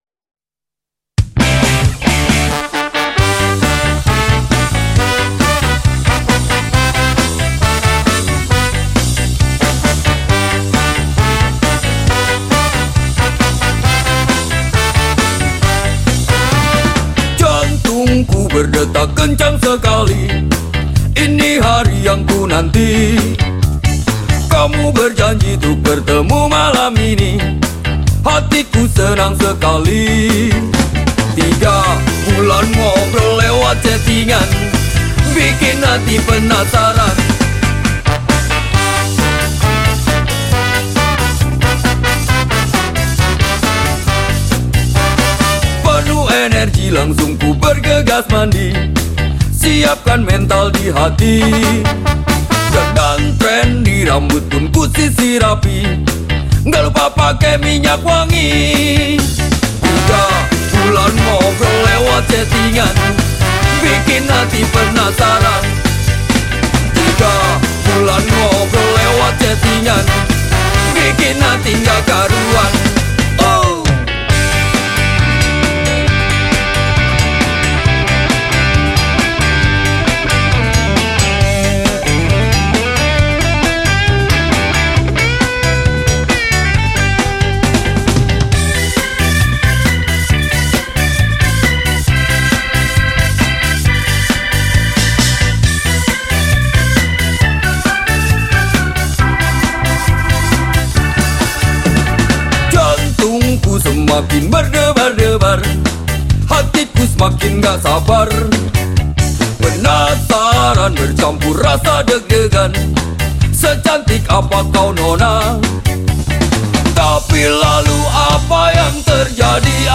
Genre Musik                              : SKA Rock, Pop